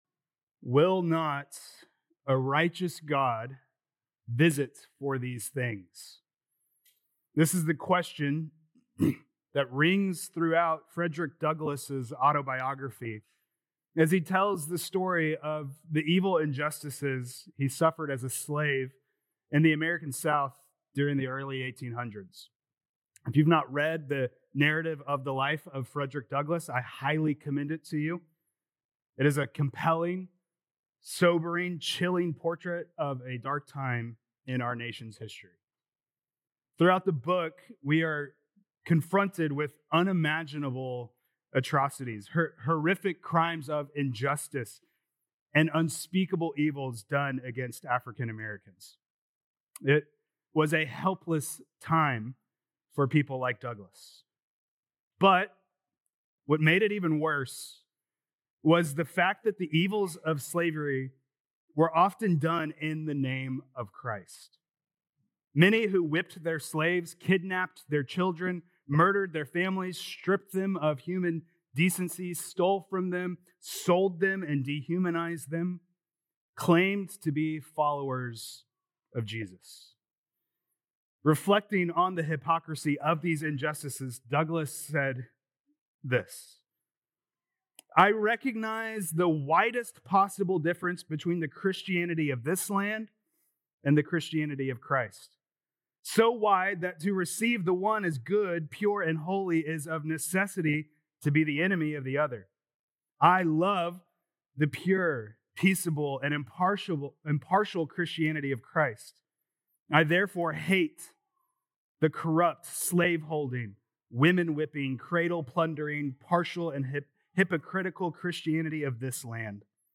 Nov 23rd Sermon